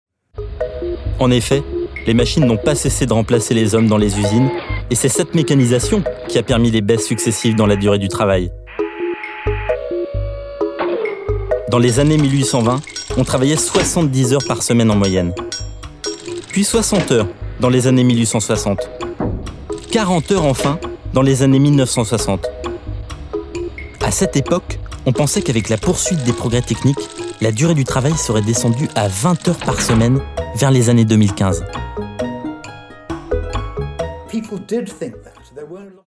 Voix off homme grave institutionnel tv
Sprechprobe: Sonstiges (Muttersprache):
Voice over man medium bass tv